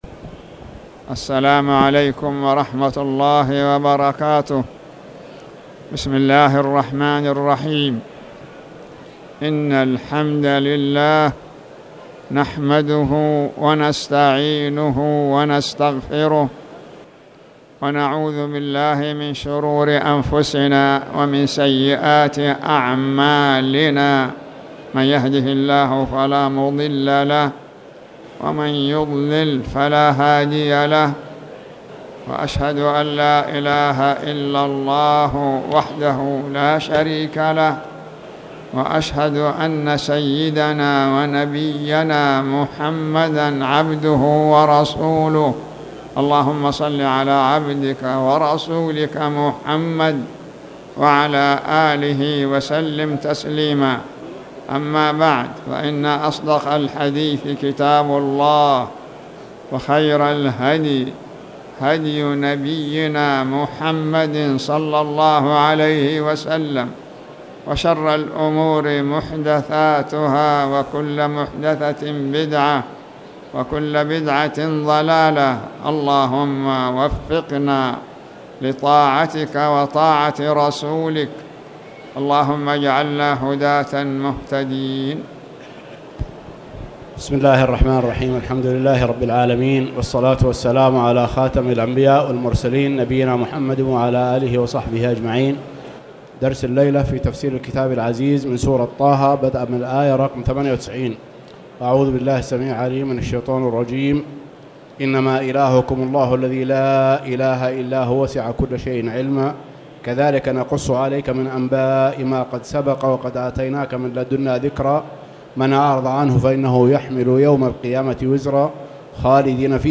تاريخ النشر ٢٩ رجب ١٤٣٨ هـ المكان: المسجد الحرام الشيخ